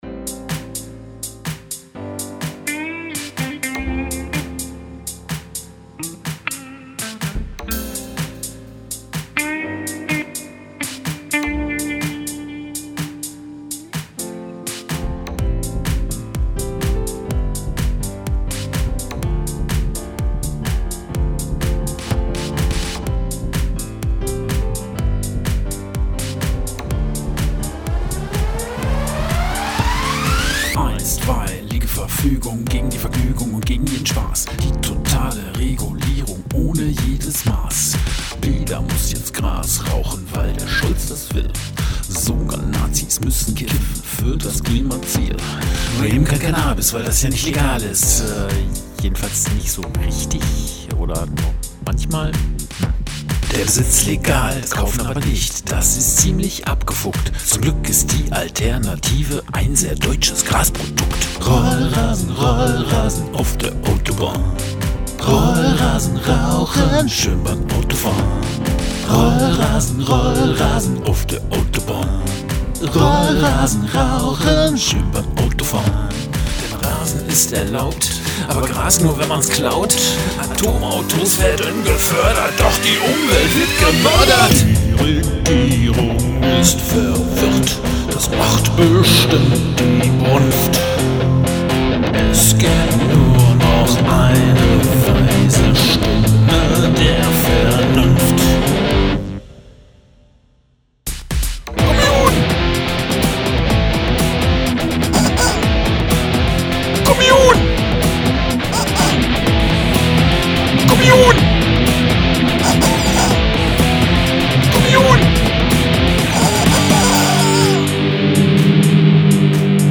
Genretechnisch würde ich diesen Song im Hip-Pop einordnen.
Änywäy, softe 125 BPM, satte 6 Minuten, Westfälischer Rap, Synthies & Gitarren, dazu NDW-, Schlager- und Grindcoreanleihen, was will man mehr?